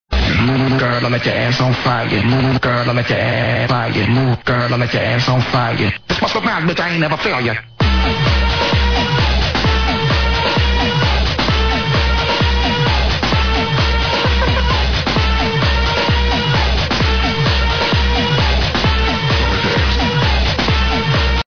fatboyslim style maybe